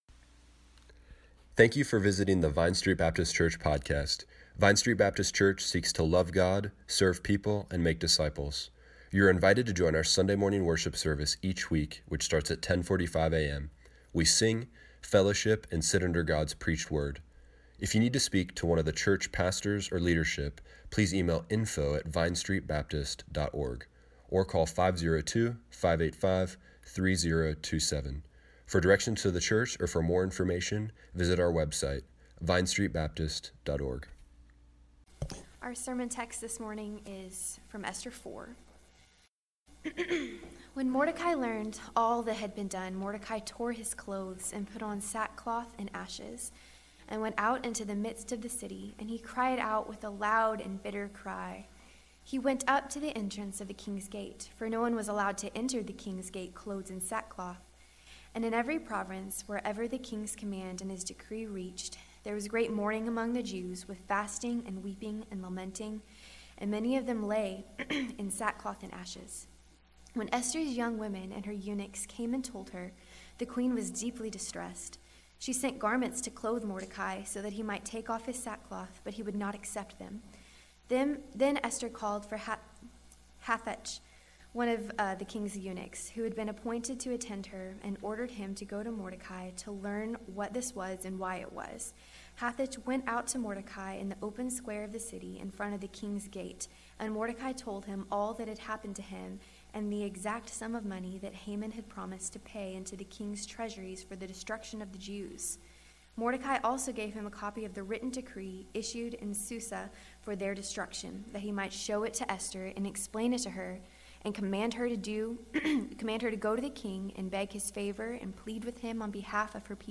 Evening Worship